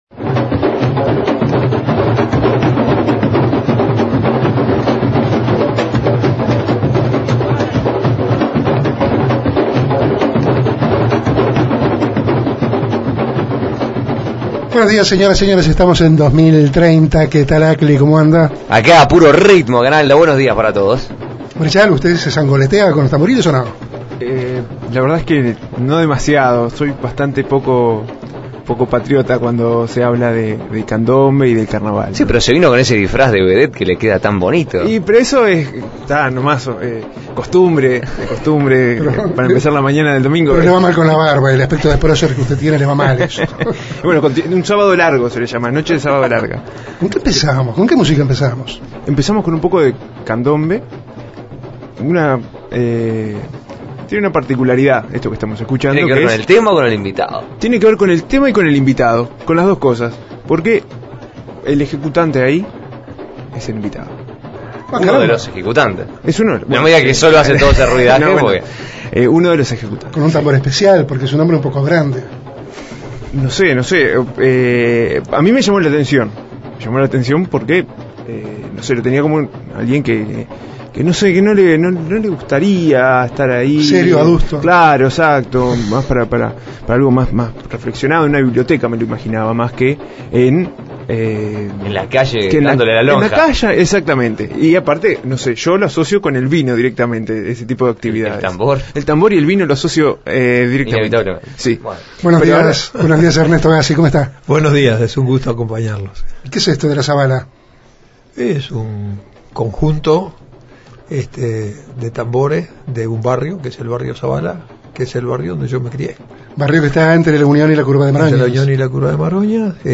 Invitado: Ernesto Agazzi.